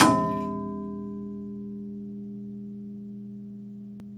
gong_1.mp3